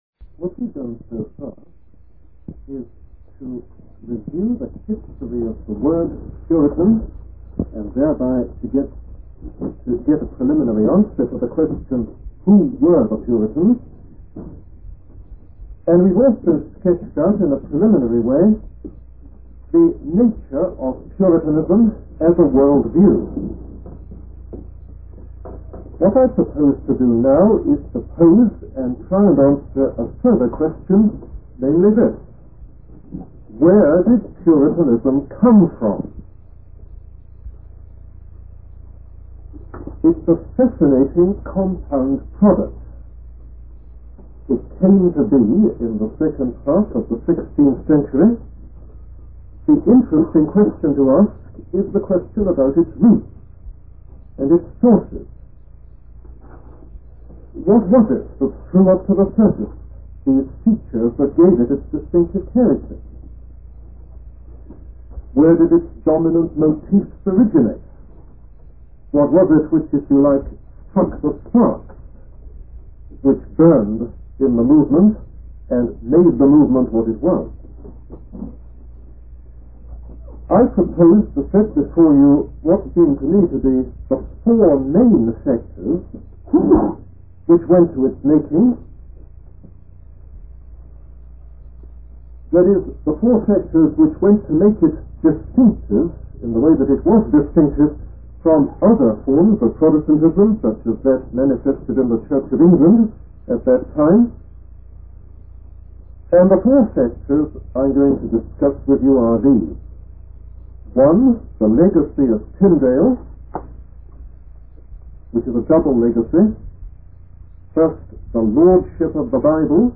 In this sermon, the speaker discusses the factors that contributed to the distinctive nature of the Protestant movement. He identifies four main sectors that played a role in shaping the movement.